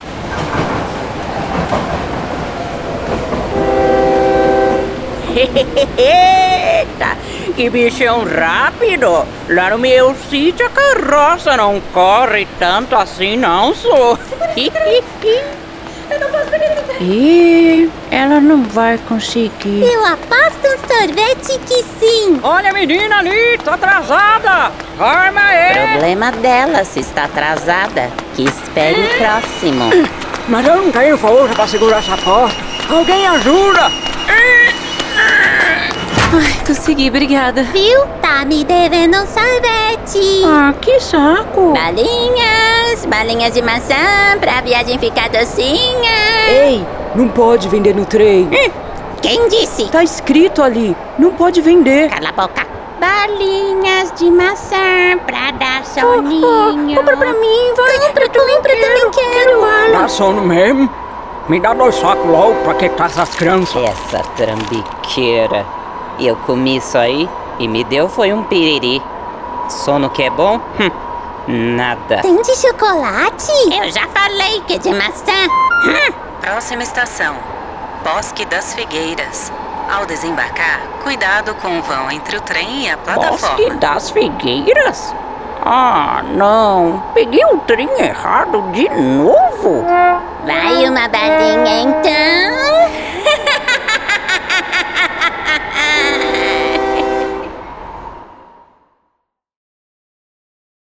Ouça meus demos de voz comercial e caricata:
Dê mais vida aos seus projetos com uma voz feminina moderna, profissional e cativante!
Desenvolvo estilos que podem variar do conversado ao promocional, passando pelo mais  tradicional, além de performar vozes caricatas de crianças e idosos.